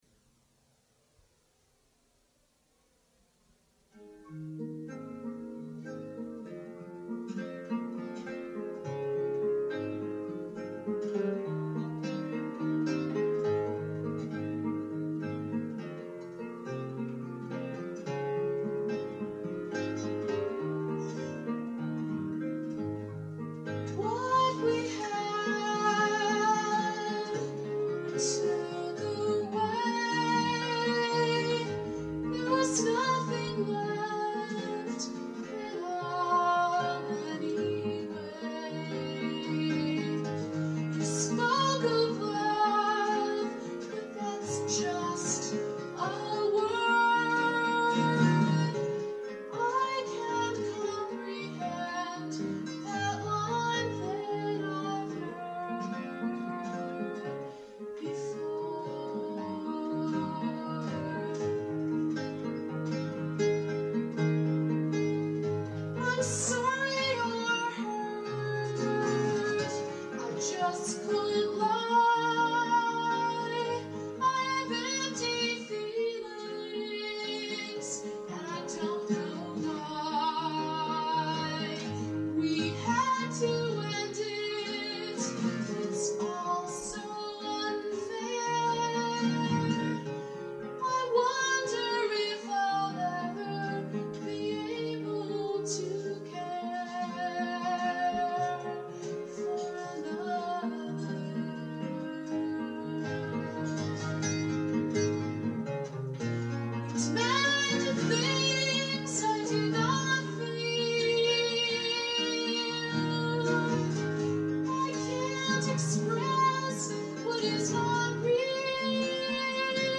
Below is a thirty-year-old recording of my song. I’m no longer embarrassed to share my younger, high voice.
Cassette Recording from 1980